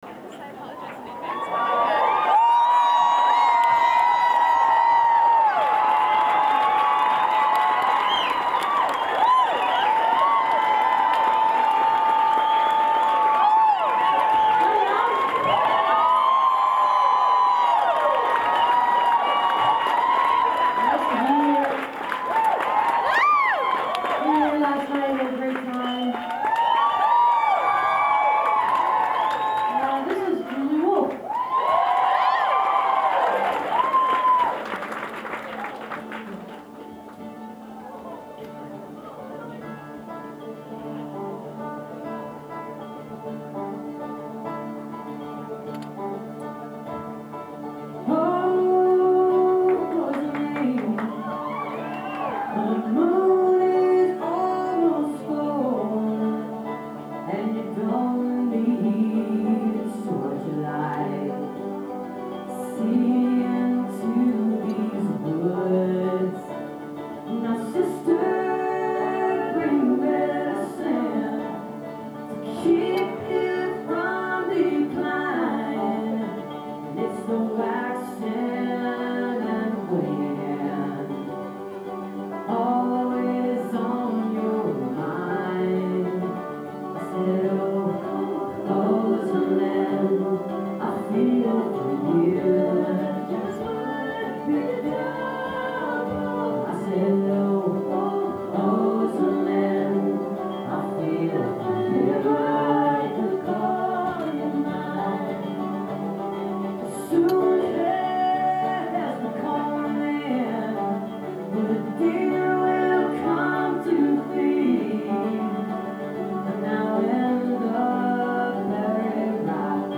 birchmere music hall - alexandria, virginia